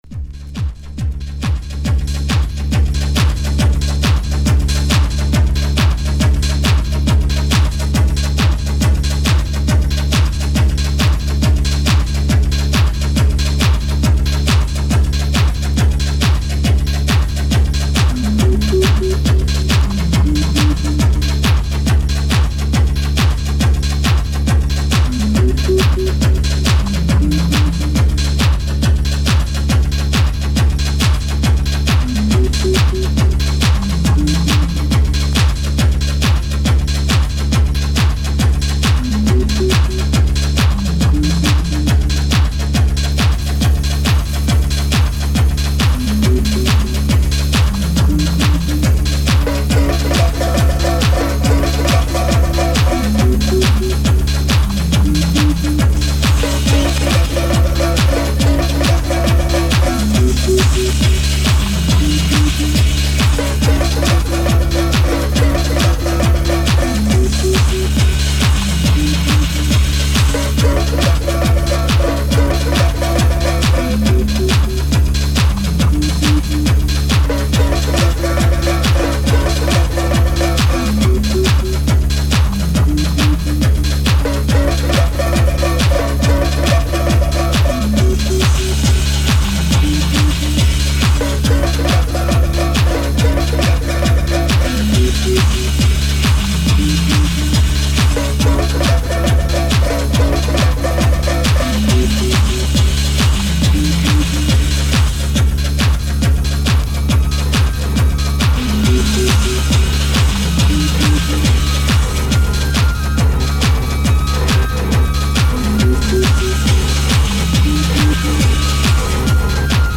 Psychedelic pass-pass